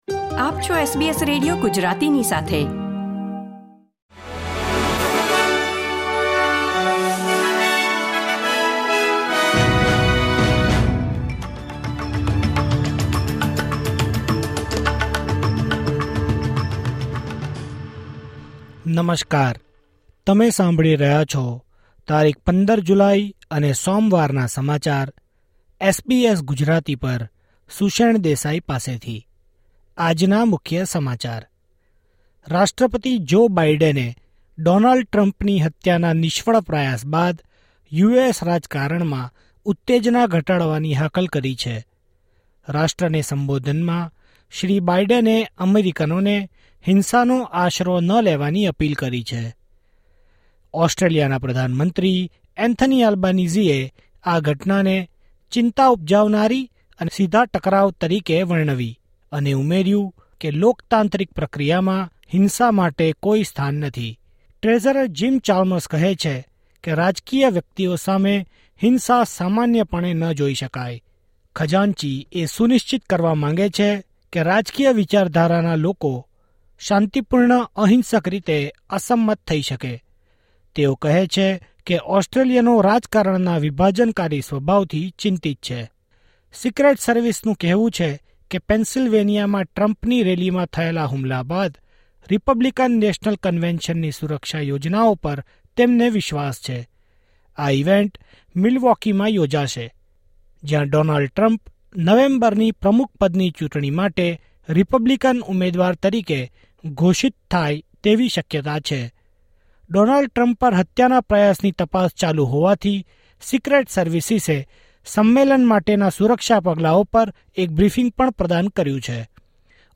SBS Gujarati News Bulletin 15 July 2024